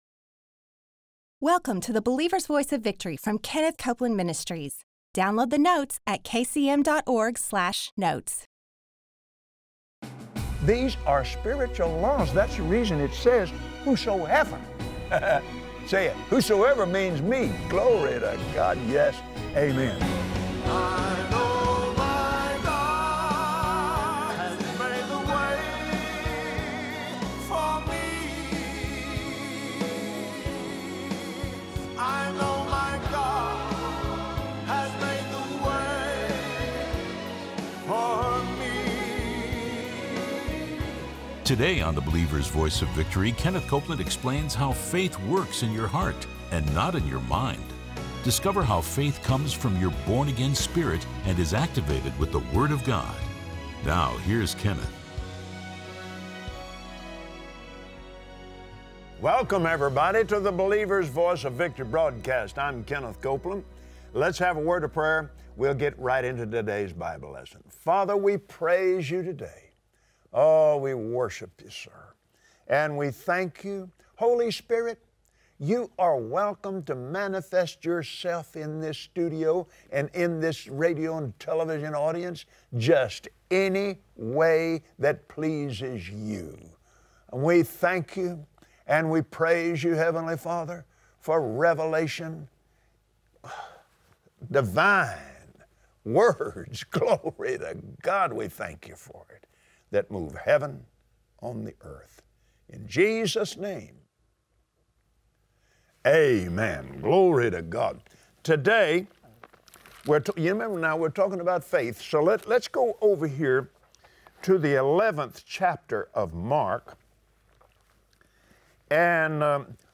Believers Voice of Victory Audio Broadcast for Tuesday 05/09/2017 Learn how to activate your faith for miracles! Watch Kenneth Copeland on Believer’s Voice of Victory explain how to put your faith to work.